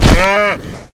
Index of /HCU_SURVIVAL/Launcher/resourcepacks/HunterZ_G4/assets/minecraft/sounds/mob/cow
hurt1.ogg